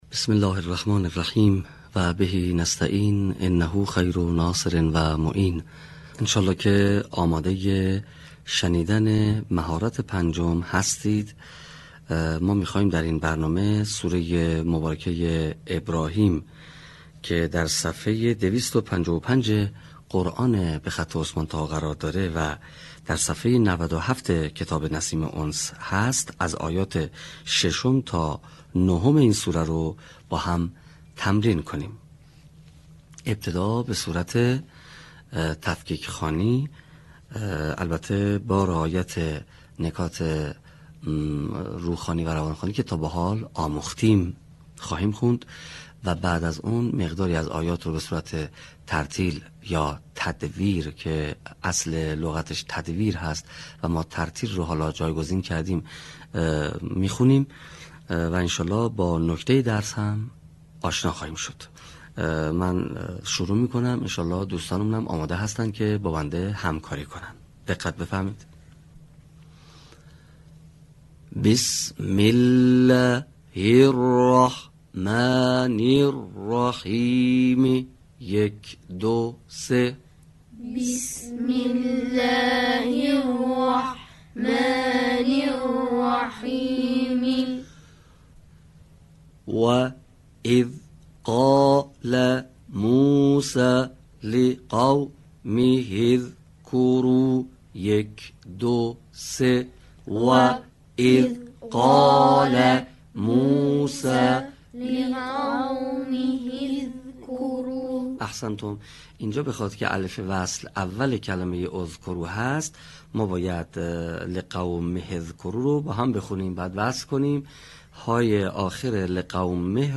صوت | مهارت روخوانی آیات ۶ تا ۹ سوره ابراهیم